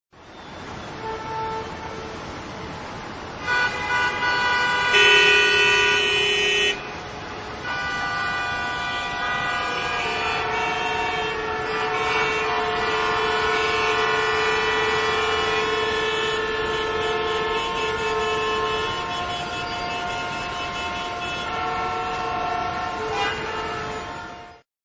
На этой странице собраны звуки автомобильных пробок — гудки машин, шум двигателей, переговоры водителей.
Шум пробки на перекрестке